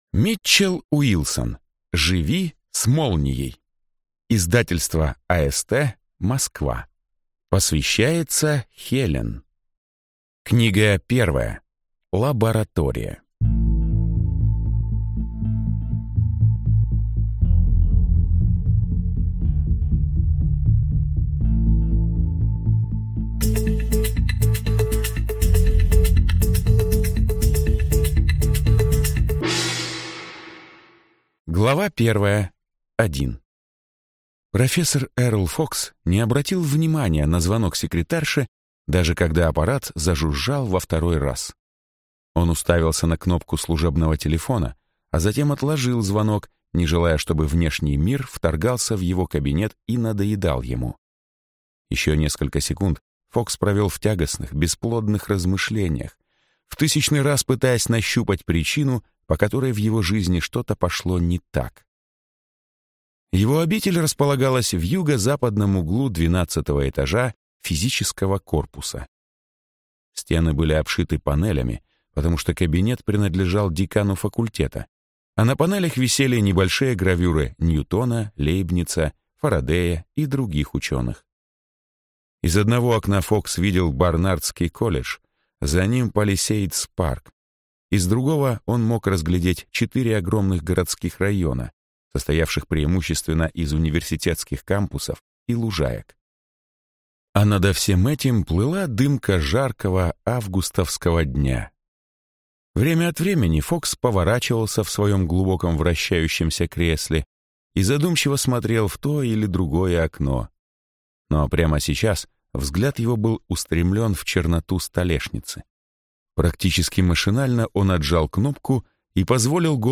Аудиокнига Живи с молнией. Книга 1. Лаборатория | Библиотека аудиокниг